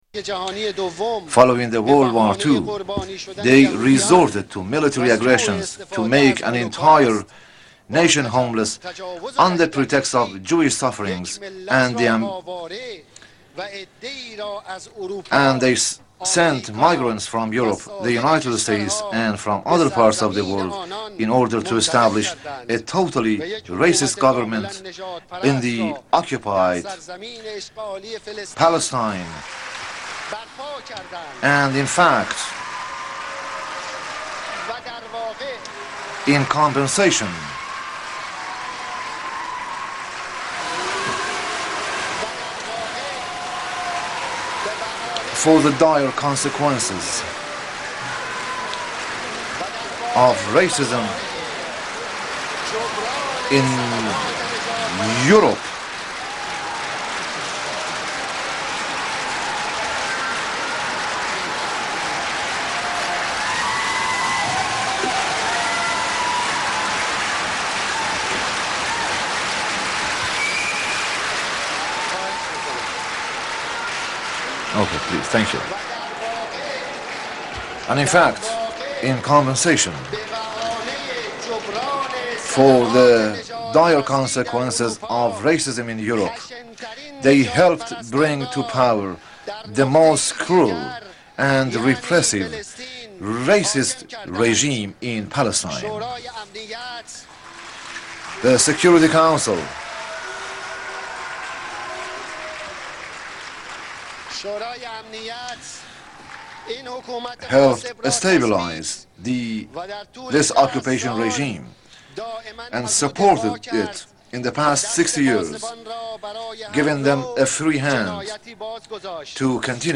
Western Diplomats Walk Out On Ahmadinejad Speech On Racism